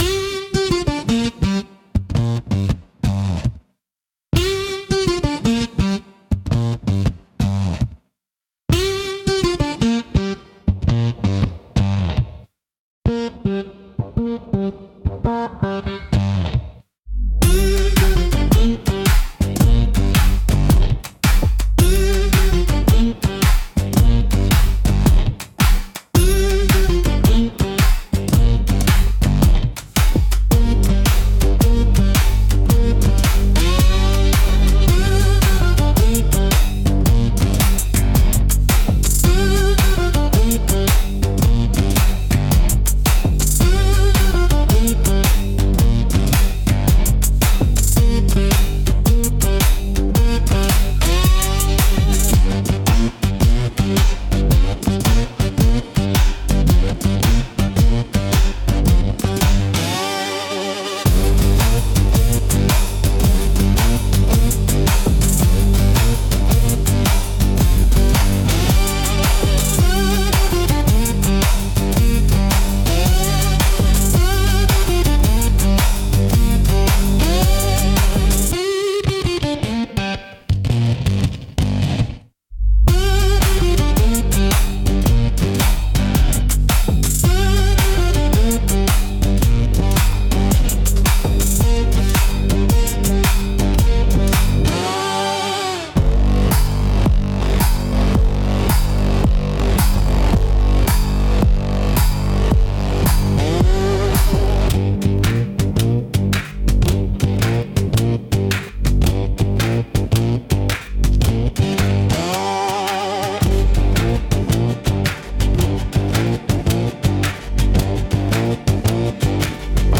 Instrumental - Blood in the Strings 2.50